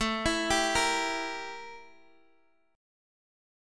Edim/A chord {x x 7 9 8 6} chord
E-Diminished-A-x,x,7,9,8,6-8.m4a